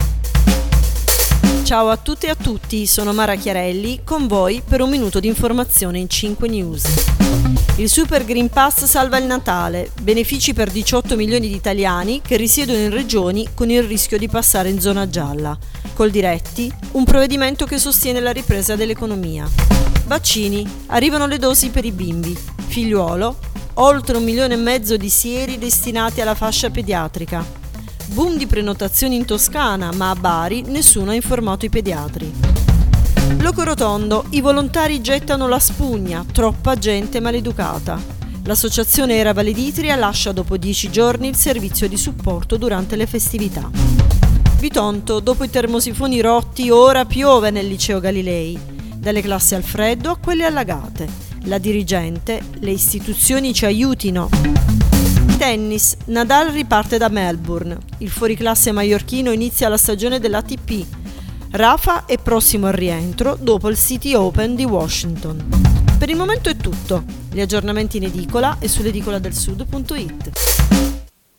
Giornale radio alle ore 7.